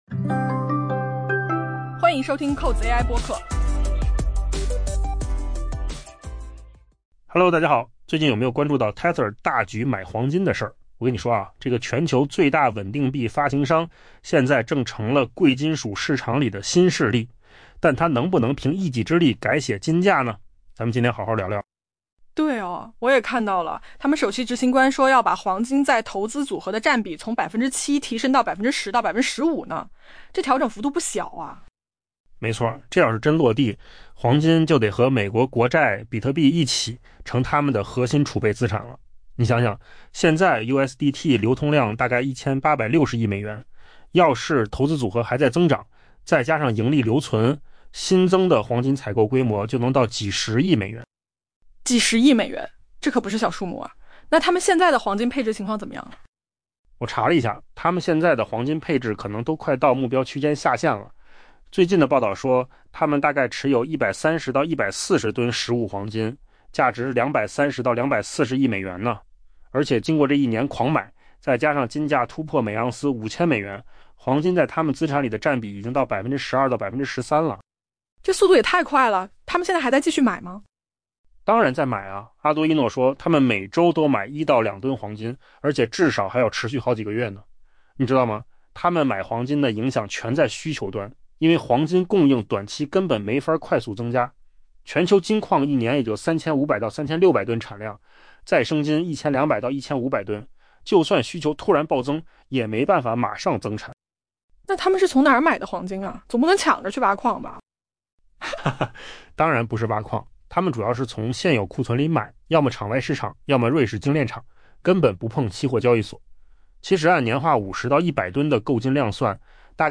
AI 播客：换个方式听新闻 下载 mp3 音频由扣子空间生成 全球最大稳定币发行商 Tether 对实物黄金的需求持续升温，正逐渐成为贵金属市场中一股不可忽视的新力量。